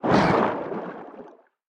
Sfx_creature_squidshark_swimangry_os_05.ogg